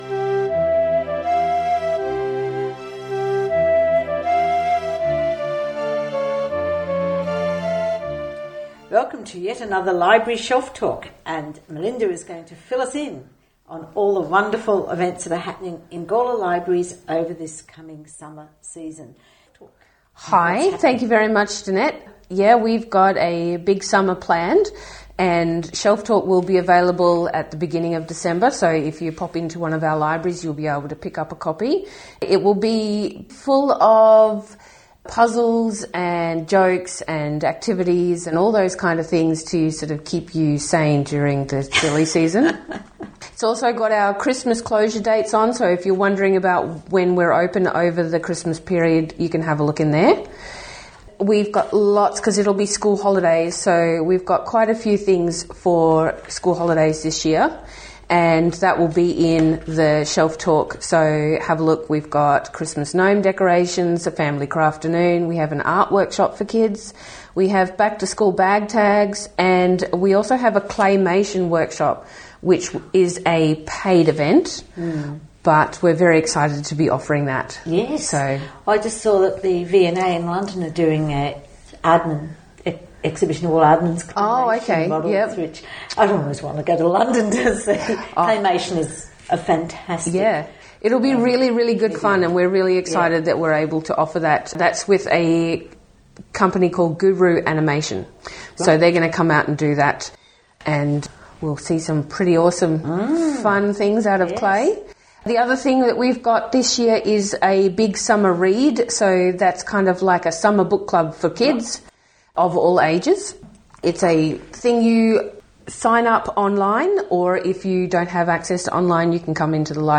Gawler Libraries, News Podcast for Summer 2025-2026 Recorded in Gawler 27/11/25 Listen to the summer Library News podcast to find out what exciting events are happening at our local library this co…